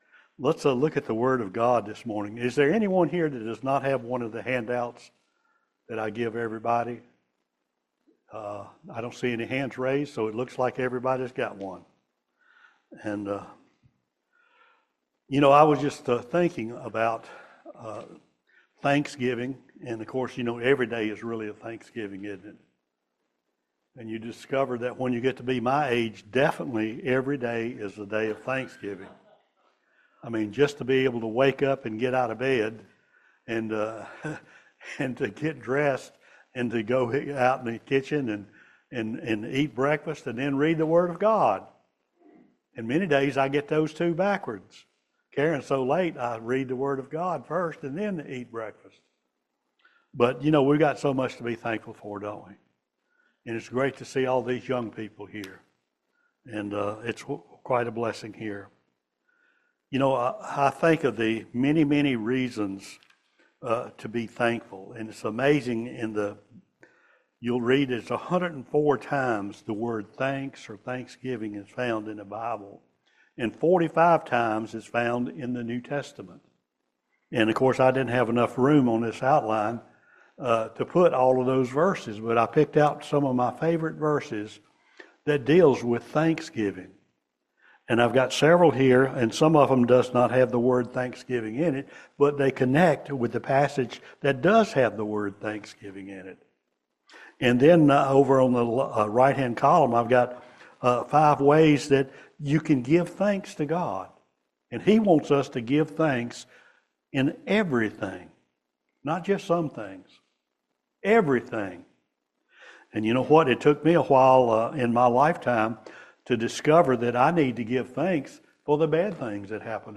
Service Type: Family Bible Hour He has given us victory over sin, death, and the grave – 1 Corinthians 15:51-58